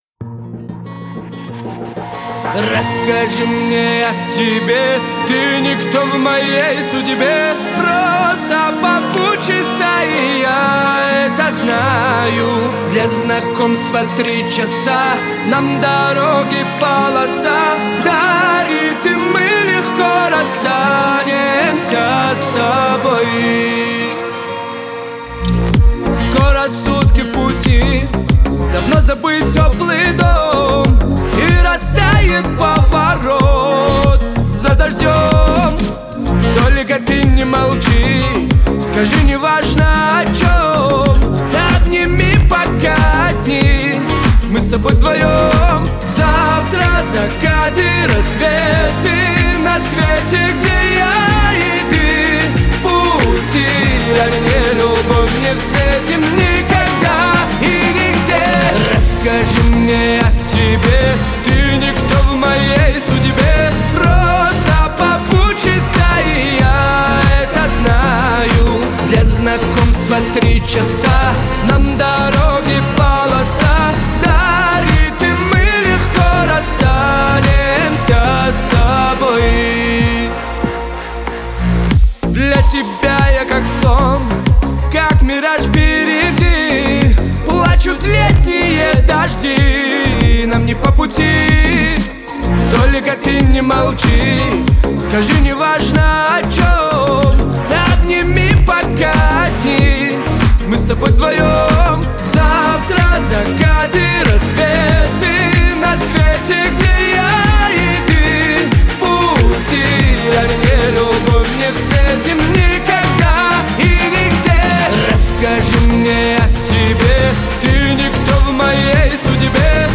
танцевальные песни
каверы